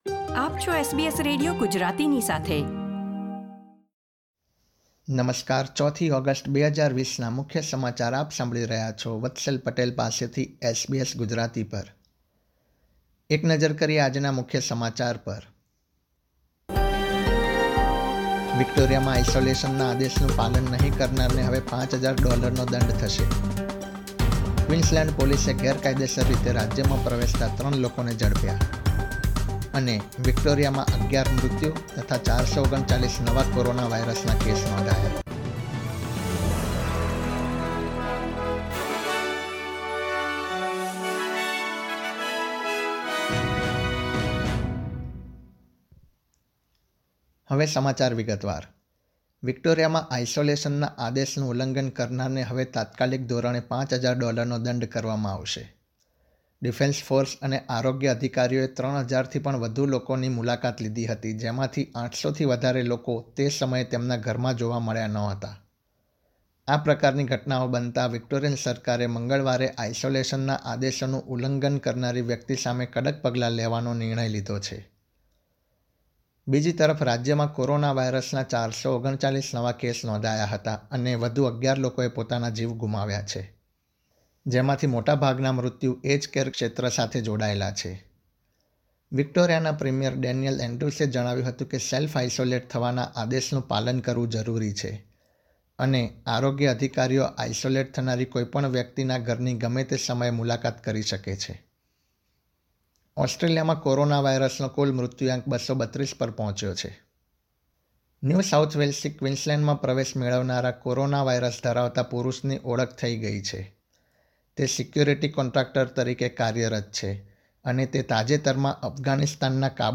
SBS Gujarati News Bulletin 4 August 2020